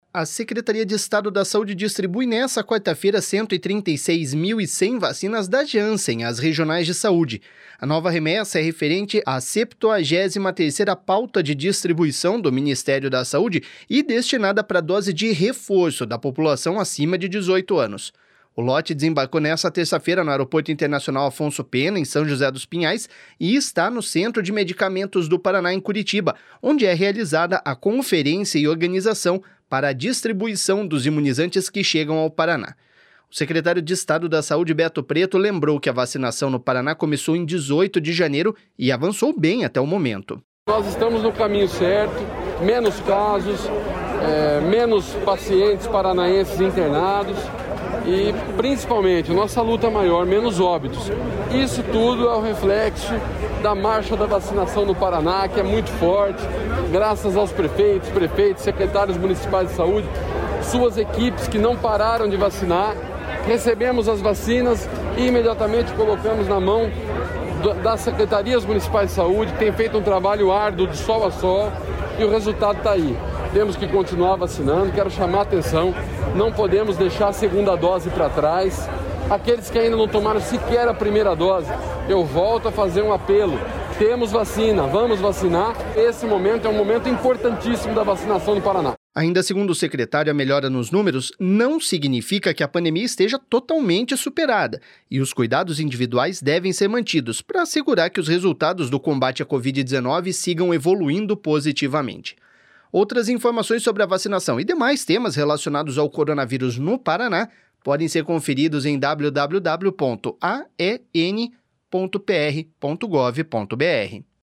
O secretário de Estado da Saúde, Beto Preto, lembrou que a vacinação no Paraná começou em 18 de janeiro e avançou bem até o momento.// SONORA BETO PRETO.//